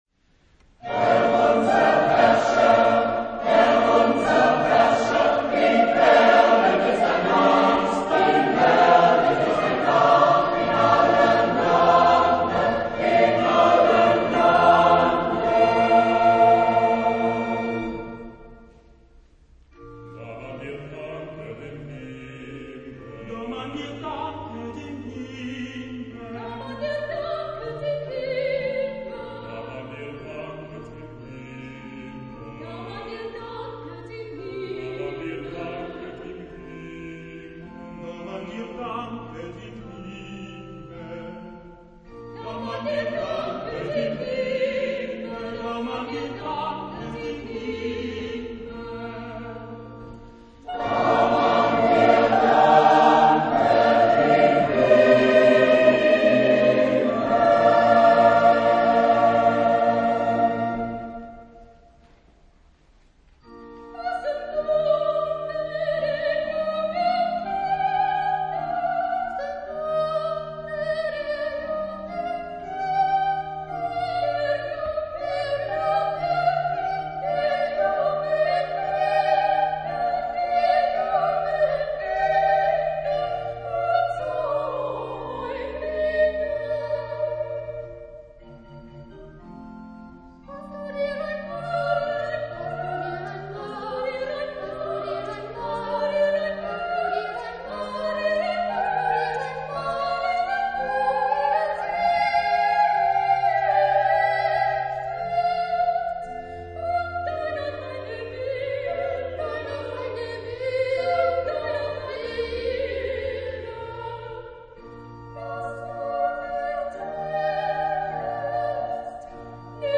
Kirchenkonzert April 2000
Motetten für achtstimmigen Doppelchor
Jahrhunderts lebte, dessen hier dargebotenes Werk "Herr, unser Herrscher" aber erfrischend modern klingt.
Solisten, Chor und Orgel